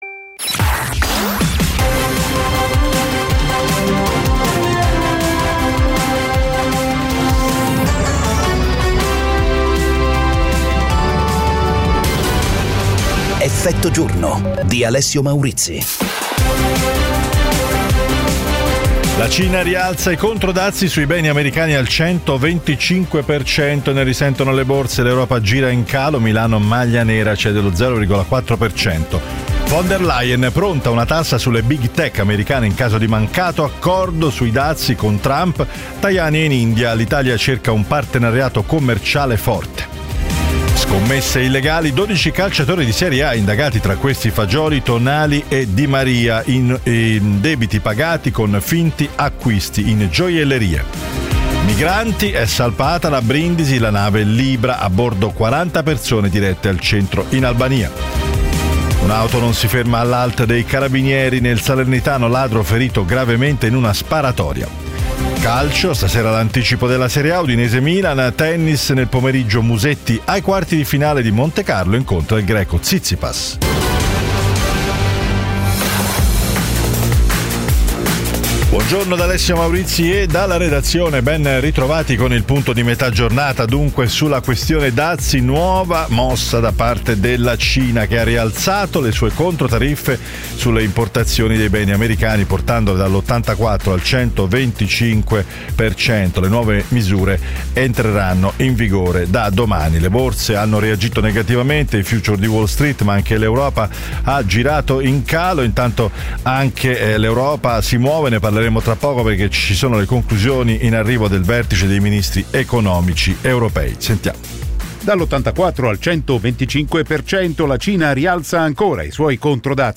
Effetto giorno è la trasmissione quotidiana che getta lo sguardo oltre le notizie, con analisi e commenti per capire ed approfondire l'attualità attraverso ospiti in diretta e interviste: politica, economia, attualità internazionale e cronaca italiana.